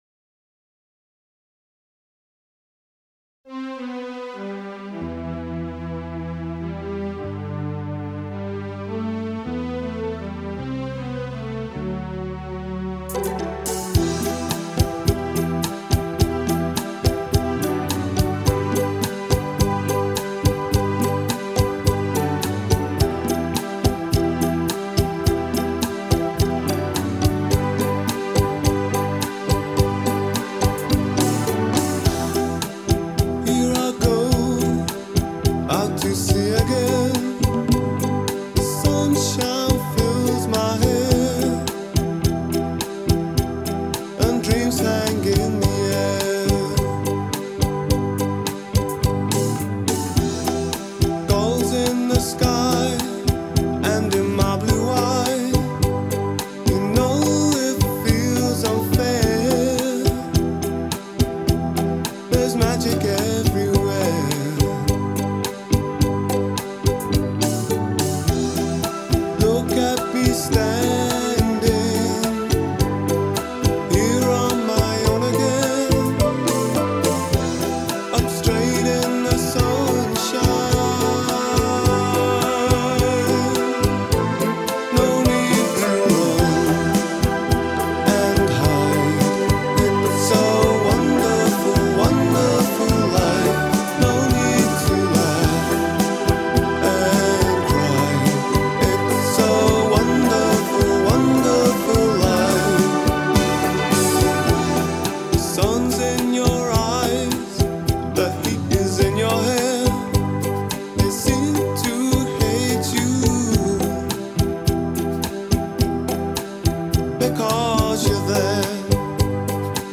by a male vocalist
vocalist/singer-songwriter